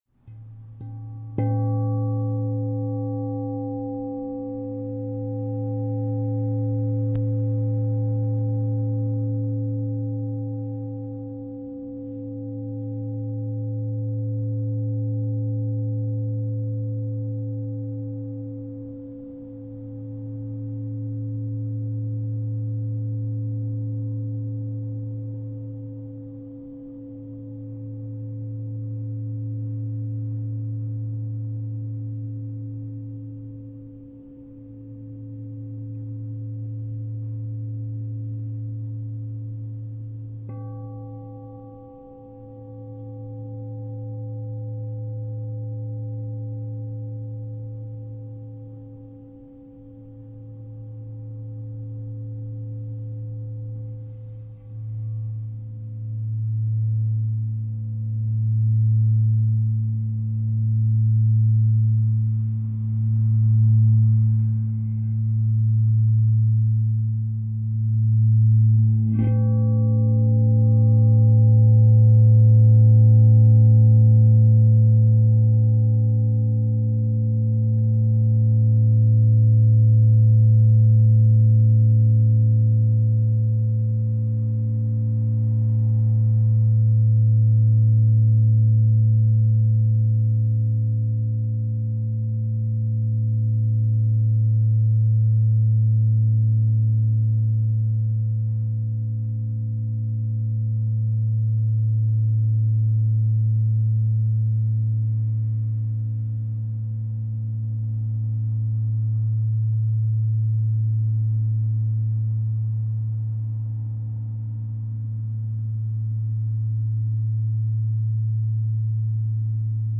16″ A 0 (True Tone)     SKU: 114656